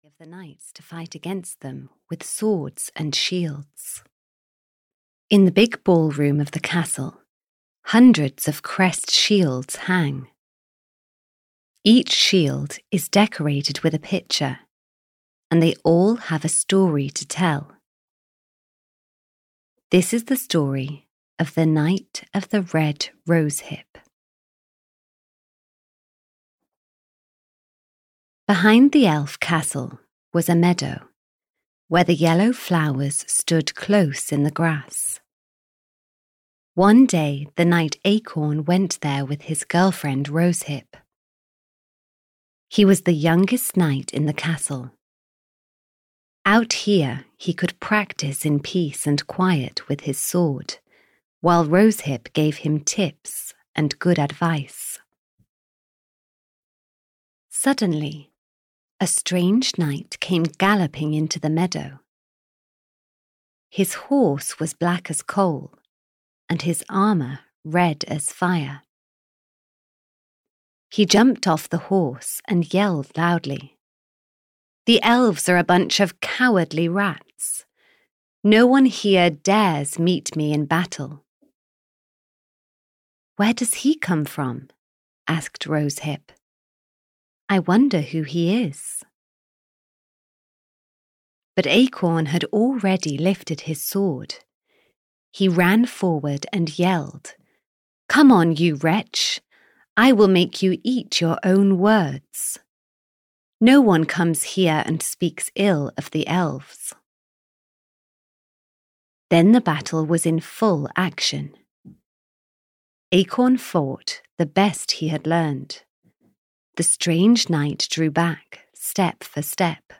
The Adventures of the Elves 1 – The Knight of the Red Rosehips (EN) audiokniha
Ukázka z knihy